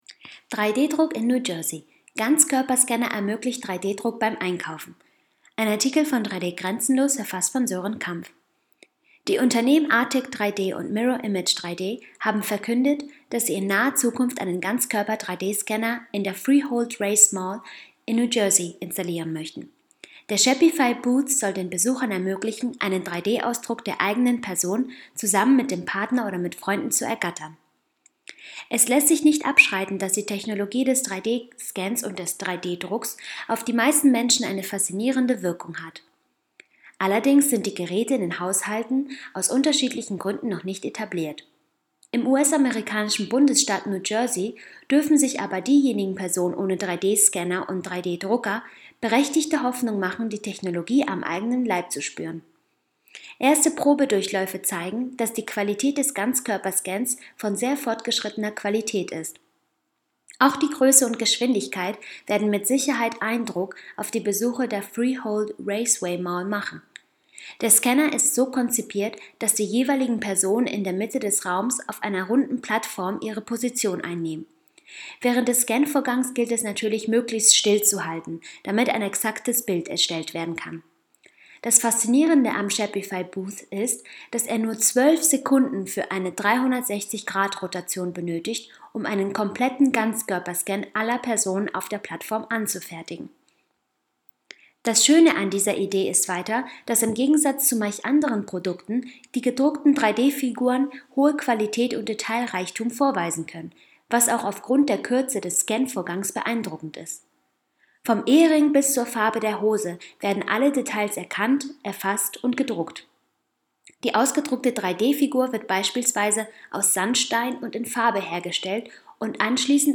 news_vorlesen_lassen_ganzkoerper-scanner_ermoeglicht_3d-druck_beim_einkaufen.mp3